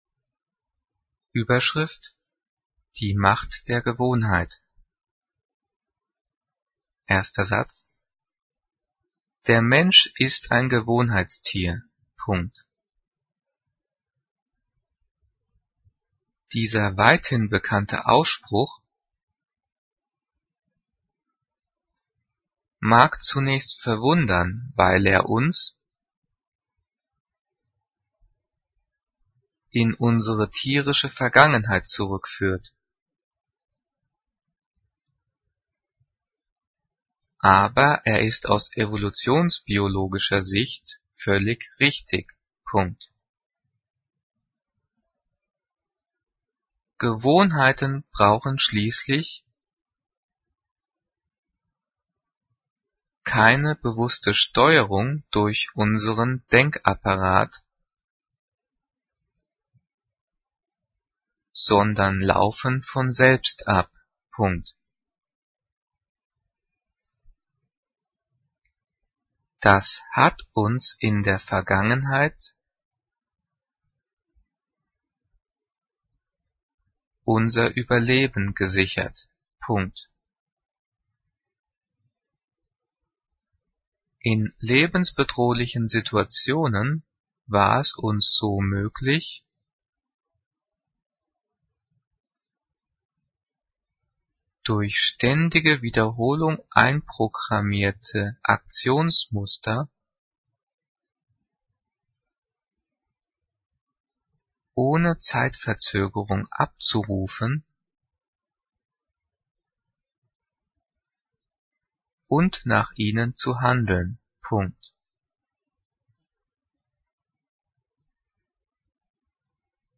Diktat: "Die Macht der Gewohnheit" - 9./10. Klasse - Getrennt- und Zus.
Diktiert: